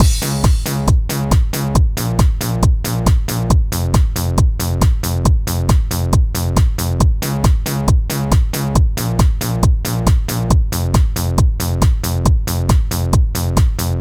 Можно вот так) Вложения RM_Children_RhythmLoop.mp3 RM_Children_RhythmLoop.mp3 550 KB · Просмотры: 594